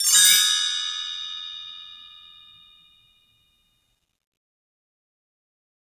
BellTree_Stroke3_v1_Sum.wav